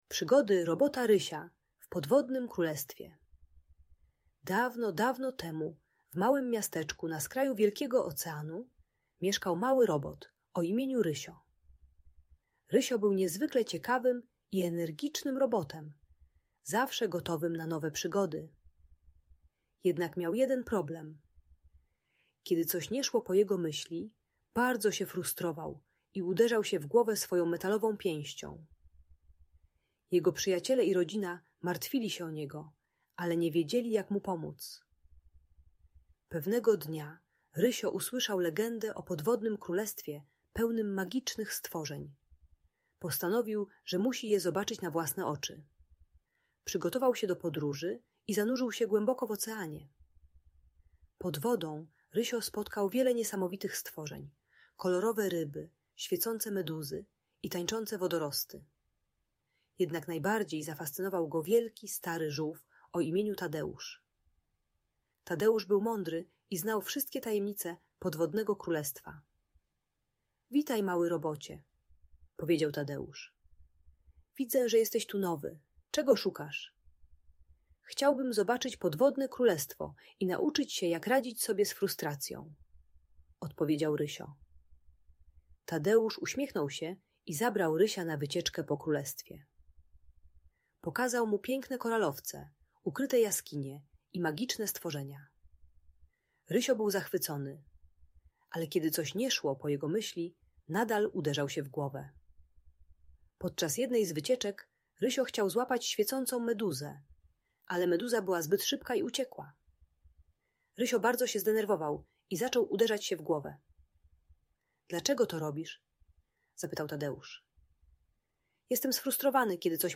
Przygody Robota Rysia - Bunt i wybuchy złości | Audiobajka